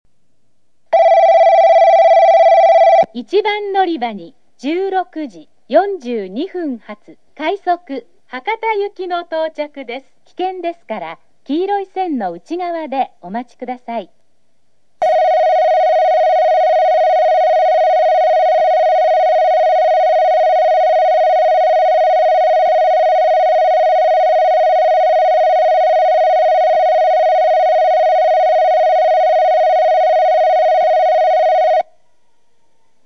＜スピーカー＞　JVC小箱　TOAホーン中
＜曲名（本サイト概要）＞　ジャクロス九州標準B　／　全線-女性
↓接近放送・・・・・おおよそ60秒前。列車によって多少の誤差があり、ベルと入線が被る。
○接近放送・女性（快速・博多） 2014年「4651H」列車です。　1番線のみJVCスピーカーに入れ替わっています。